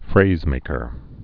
(frāzmākər)